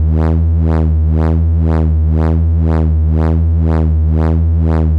For example, moving it by 1⁄4 cycle so the distortion treats the peak of a triangle wave as the beginning, then Wtri makes a plain triangle at 50%, but morphs towards becoming a sawtooth as the duty cycle changes, as in the following example where a 1 Hz LFO moves the duty cycle in the range 5%–95%.
pd-trisaw.mp3